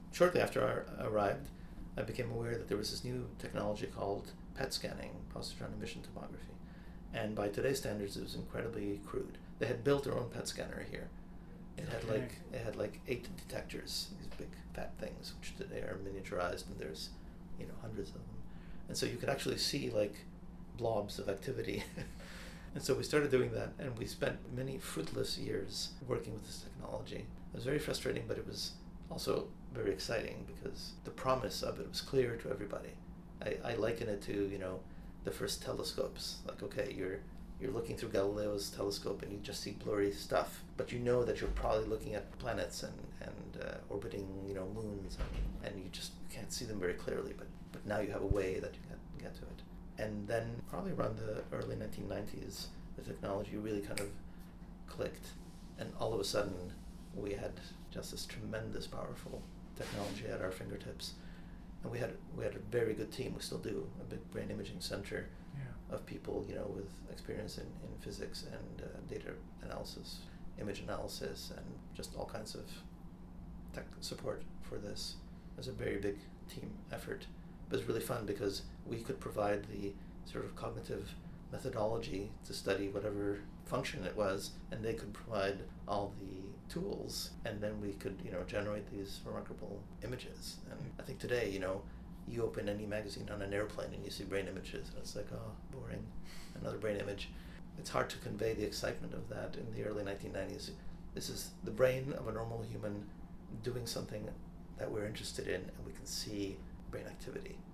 In this final excerpt from our discussion, Dr. Zatorre describes the emergence of this technology for neuroimaging (and PET, in particular).